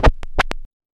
Sample Sound Effects
vinyl-record-scratch.mp3